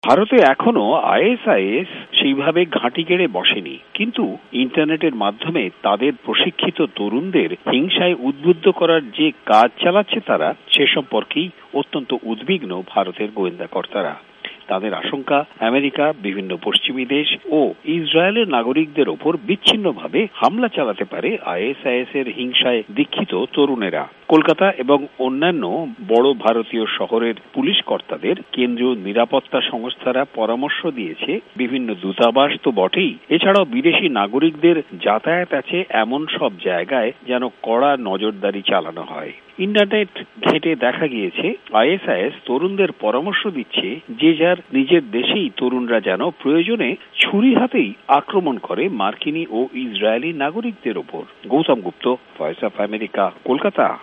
ভয়েস অব আমেরিকার কলকাতা সংবাদদাতাদের রিপোর্ট: